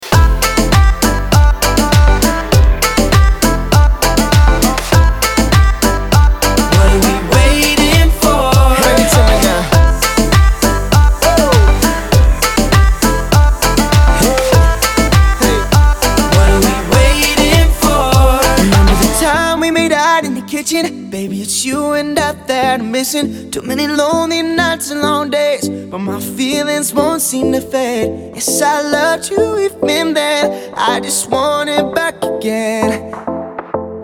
• Качество: 320, Stereo
поп
мужской вокал
громкие
веселые
dance
электронная музыка